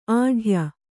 ♪ āḍhya